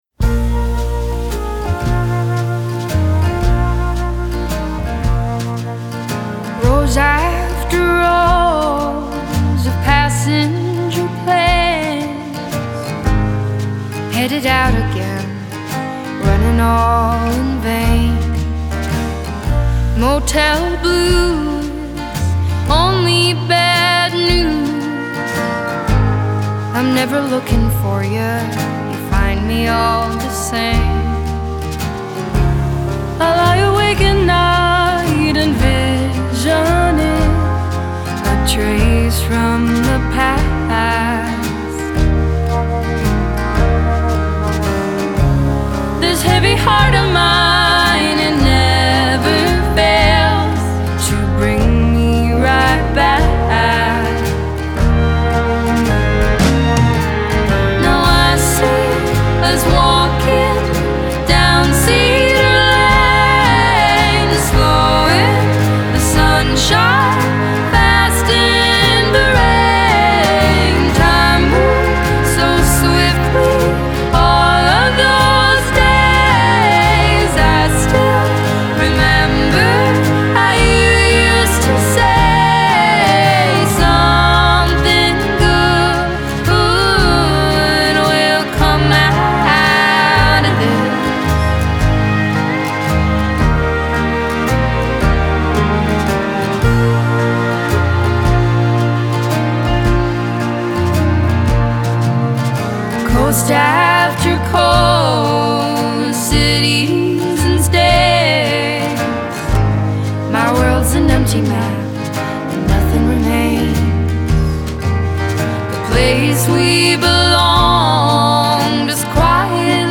Genre: Indie Pop, Indie Folk